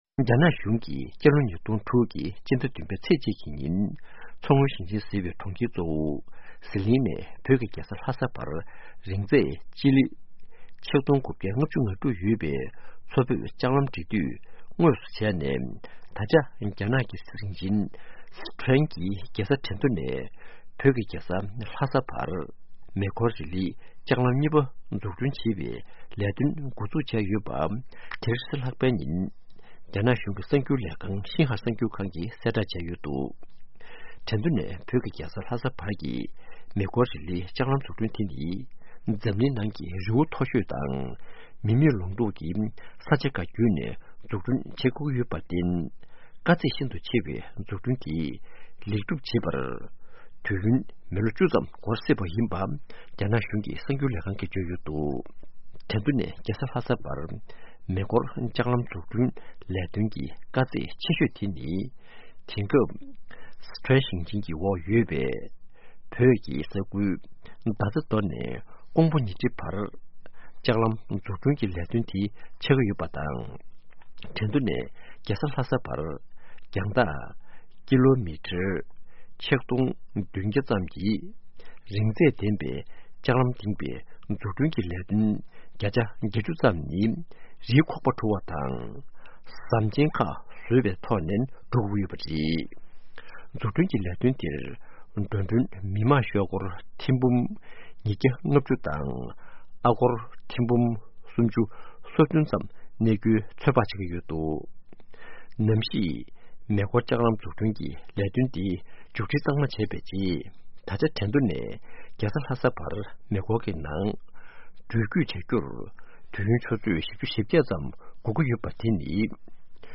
ཕབ་བསྒྱུར་དང་སྙན་སྒྲོན་ཞུ་གི་རེད།